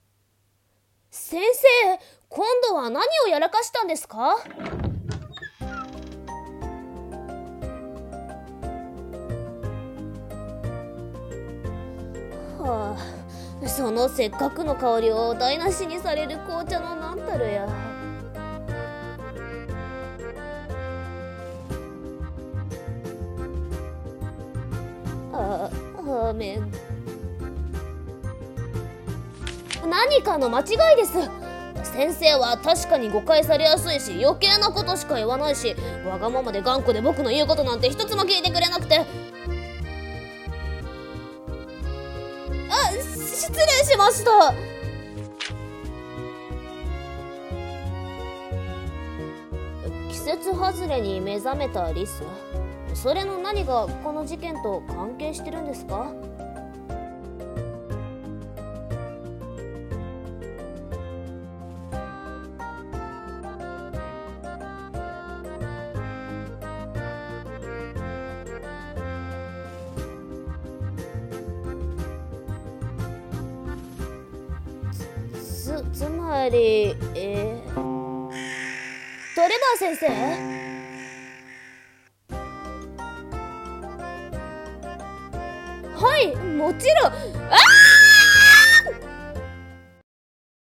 【コラボ用声劇】 難題なレーツェル